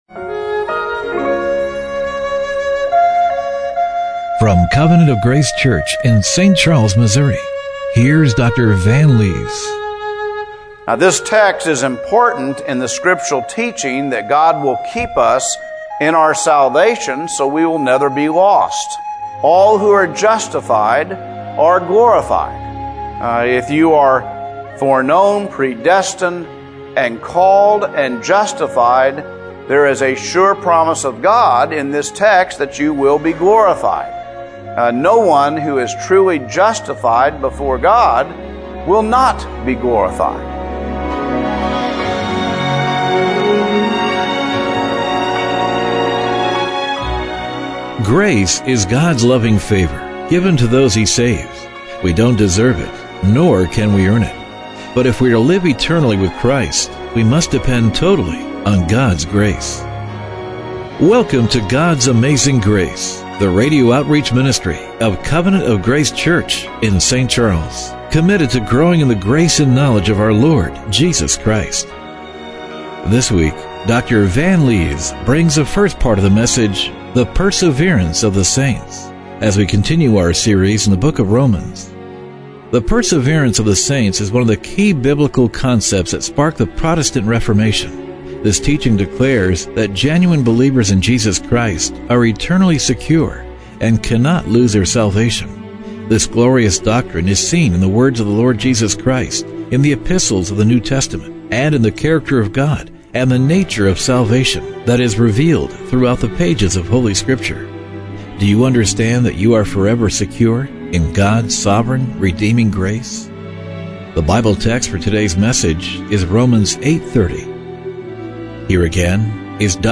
Romans 8:30 Service Type: Radio Broadcast Do you understand that you are forever secure in God's sovereign